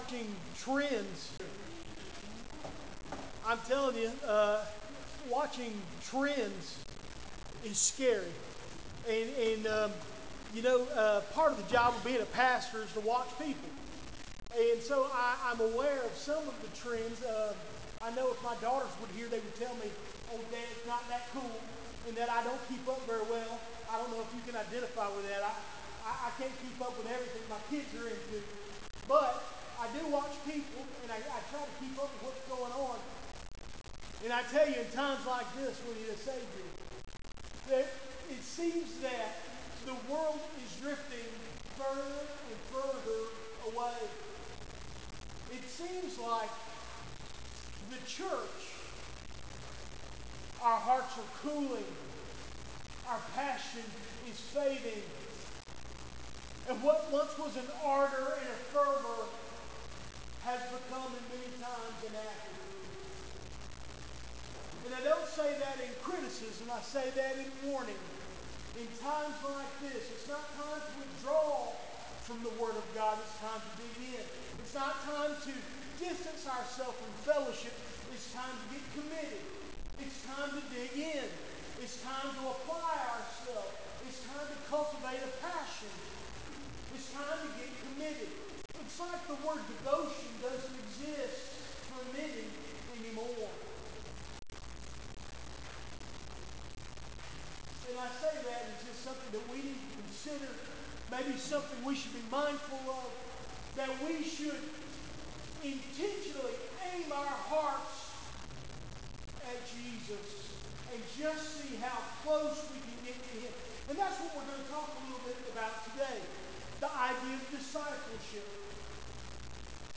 Recent Sermons - Doctor's Creek Baptist Church